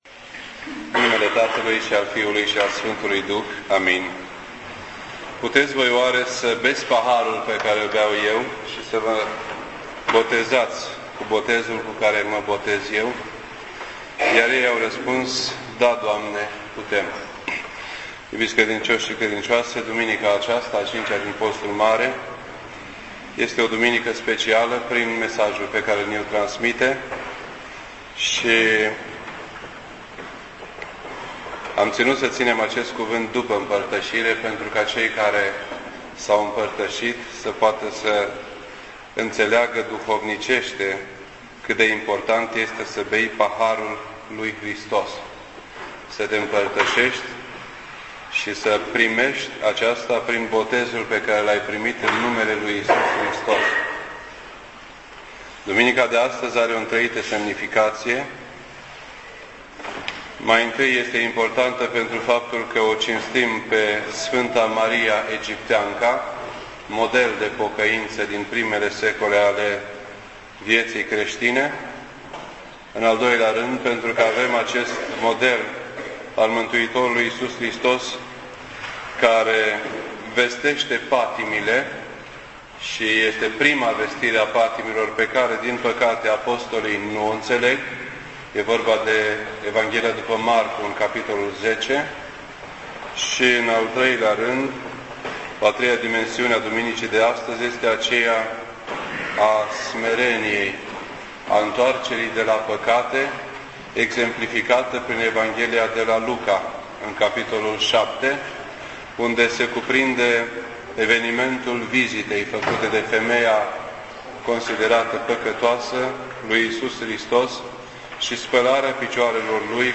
This entry was posted on Sunday, April 5th, 2009 at 6:51 PM and is filed under Predici ortodoxe in format audio.